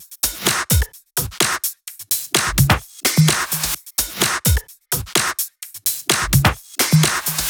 VFH2 128BPM Capone Kit 4.wav